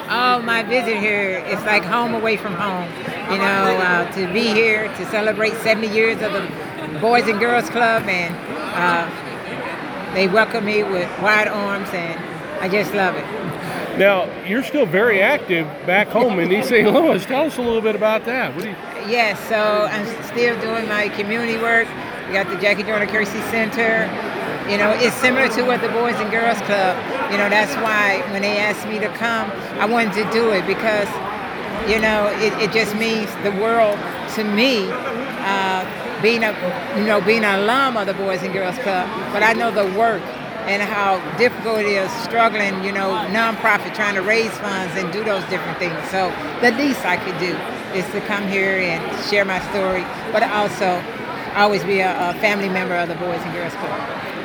Six-time Olympic medalist Jackie Joyner-Kersee served as the keynote speaker at the Boys & Girls Clubs of Bartlesville's annual Great Futures Luncheon on Friday, December 13th.
AUDIO: Jackie Joyner-Kersee talks Bartlesville and The Boys and Girls Club